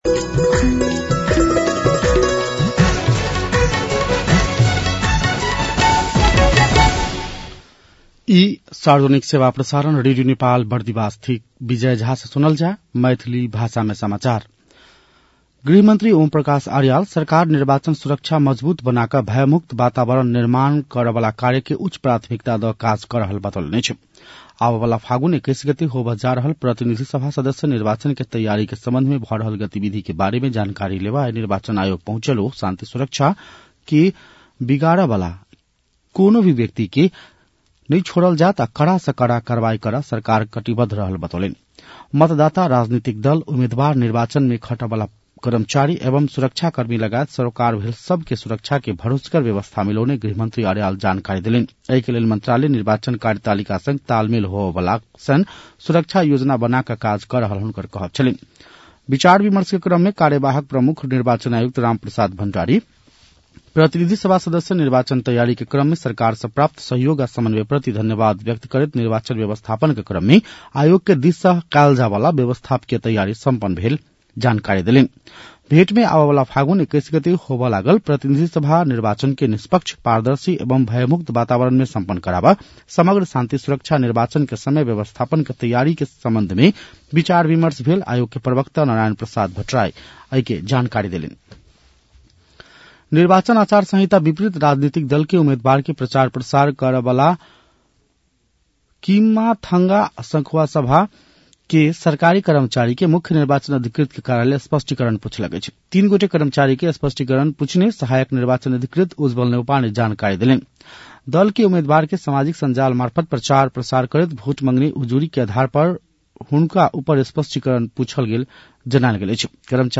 मैथिली भाषामा समाचार : १५ माघ , २०८२
6.-pm-maithali-news-1-8.mp3